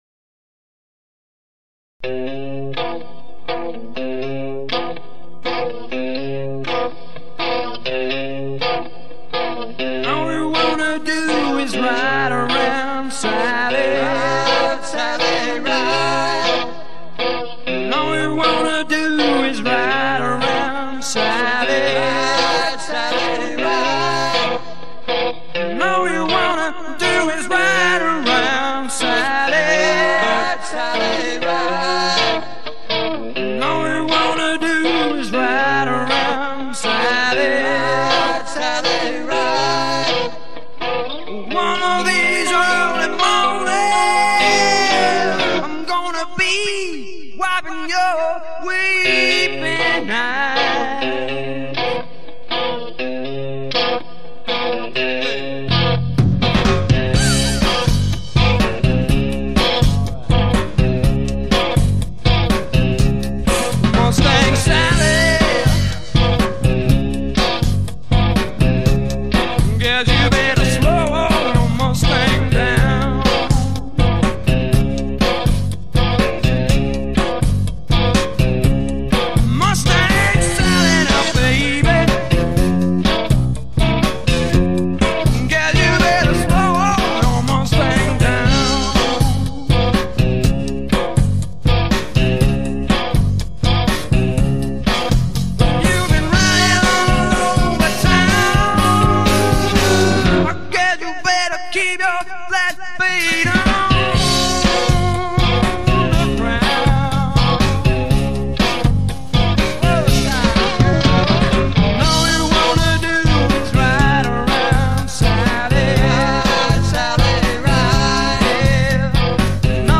guitar/vocals